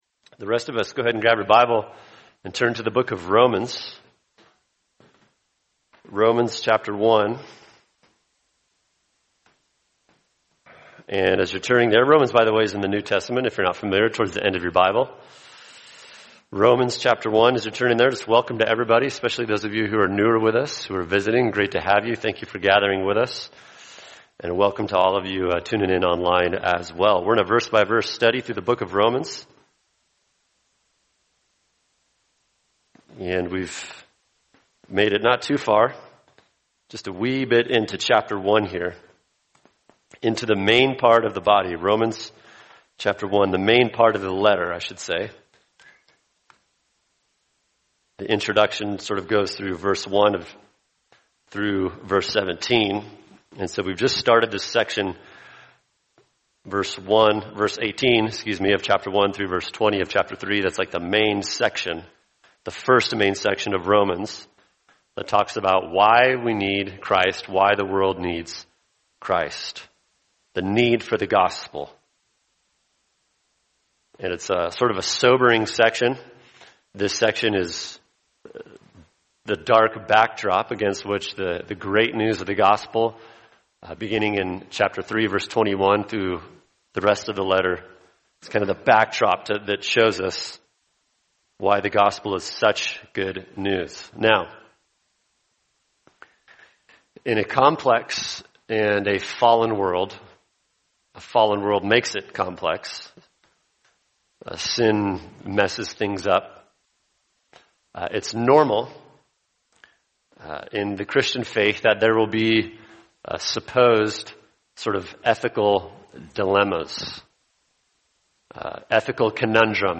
[sermon] Romans 1:19-20 Reasons for God’s Wrath: The Suppression of General Revelation – Part 2 | Cornerstone Church - Jackson Hole